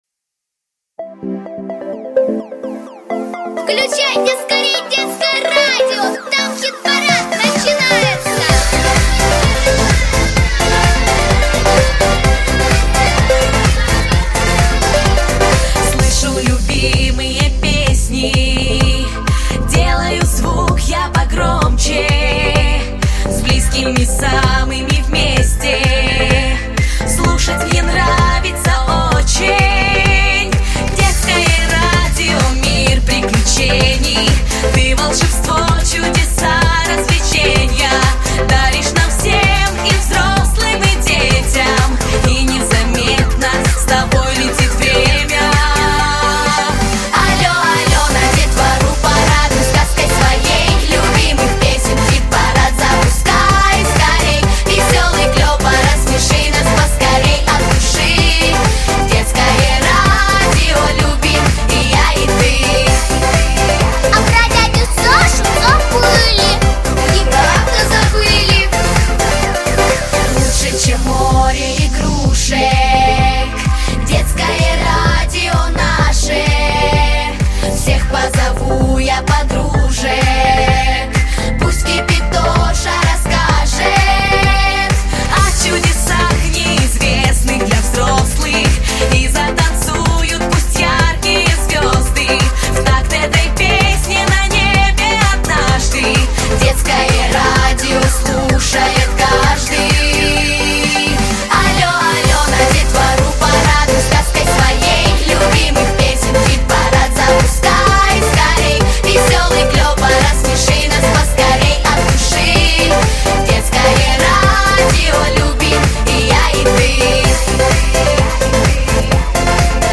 • Жанр: Детские песни
Песни в исполнении детской музыкальной студии